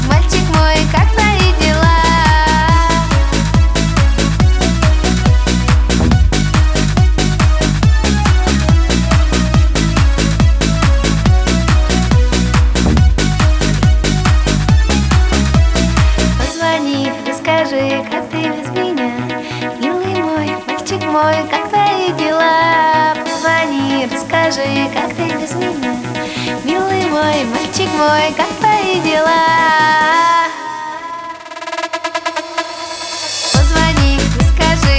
Жанр: Поп / Русские